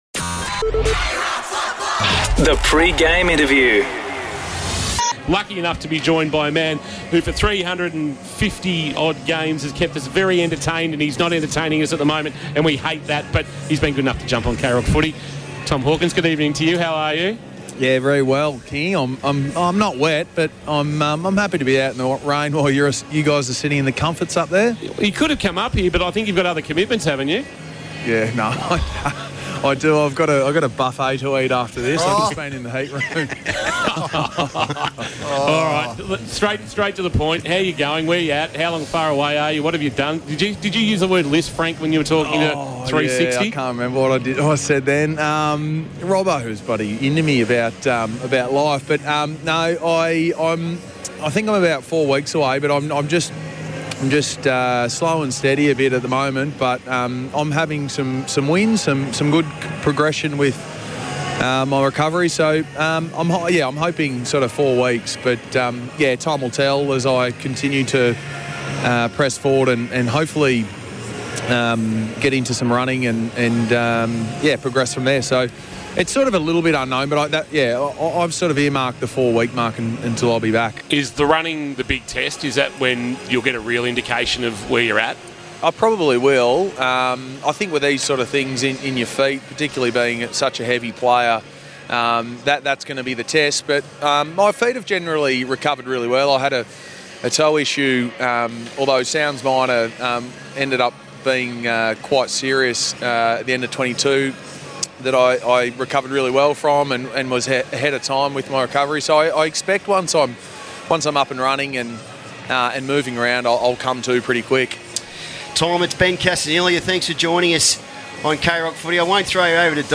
2024 - AFL - Round 19 - Geelong vs. Western Bulldogs: Pre-match interview - Tom Hawkins (Geelong Cats)